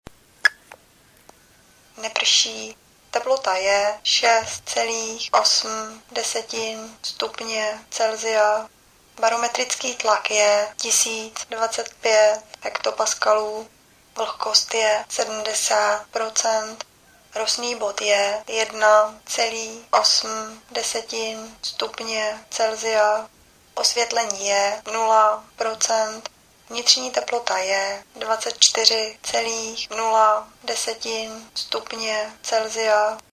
Pokud je připojena venkovní sonda, dozvíme se tyto informace.